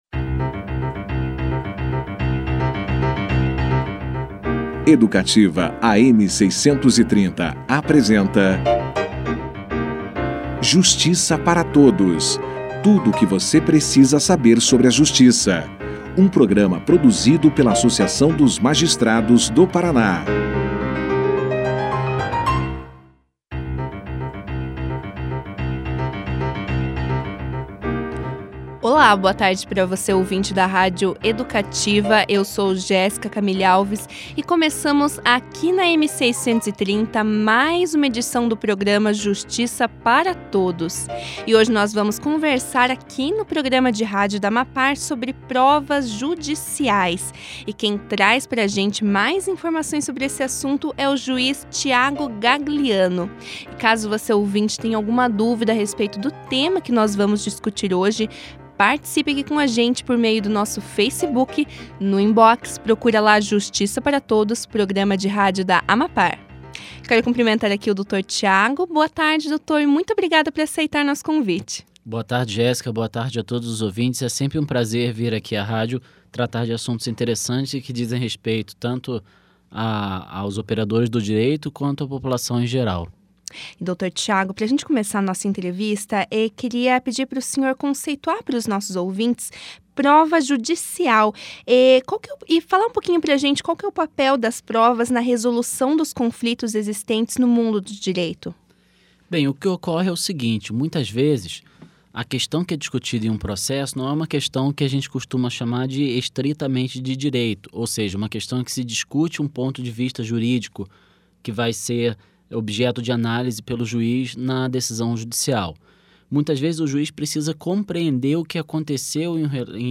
Juiz Tiago Gagliano fala sobre provas judiciais no programa de rádio da AMAPAR
Durante a conversa, o magistrado também falou a respeito dos conceitos de verdade real e formal e, esclareceu ainda, como funciona o sistema de avaliação de provas judiciais. Para finalizar a entrevista, o jurista destacou algumas mudanças trazidas pelo novo Código de Processo Civil, em relação ao sistema de provas. Confira aqui a entrevista na íntegra.